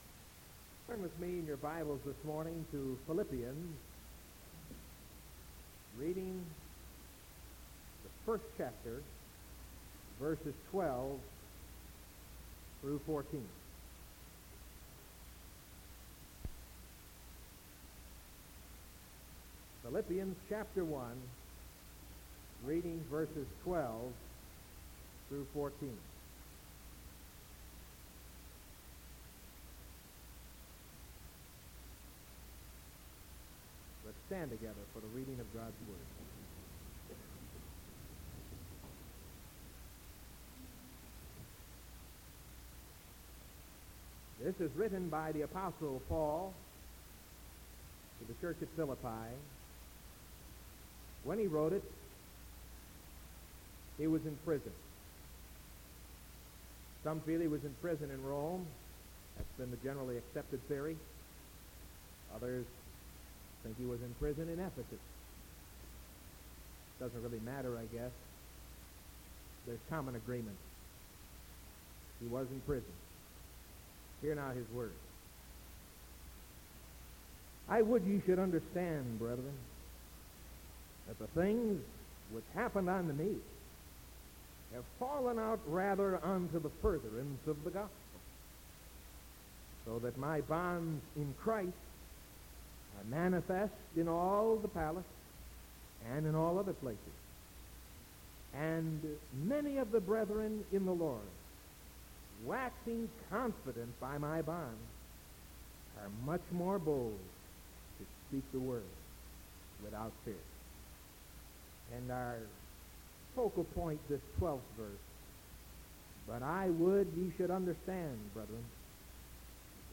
Sermon from March 31st 1974 AM